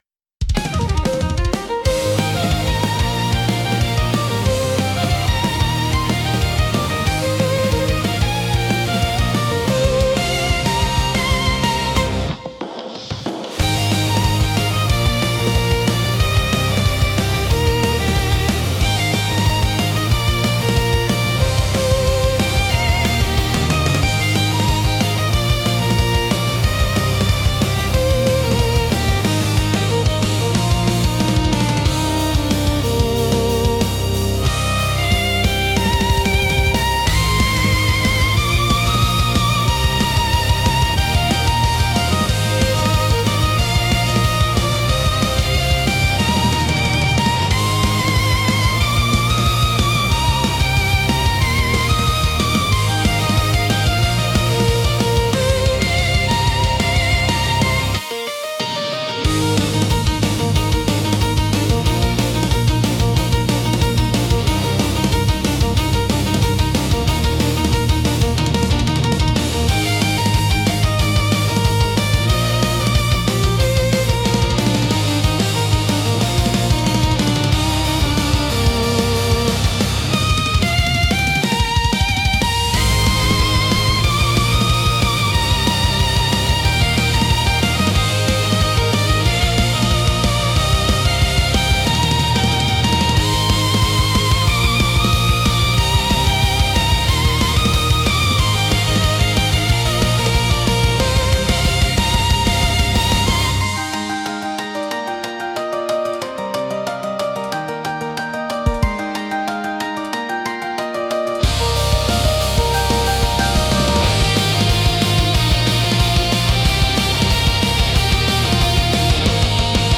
公開している音楽は、映画やアニメ、ゲームに想定したBGMや、作業用のBGMを意識して制作しています。